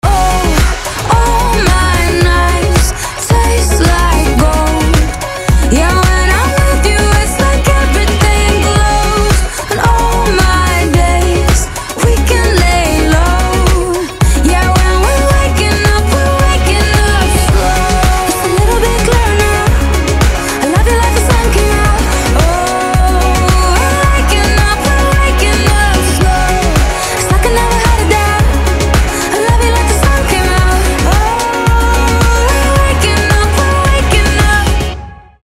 • Качество: 320, Stereo
поп
женский вокал
зажигательные
dance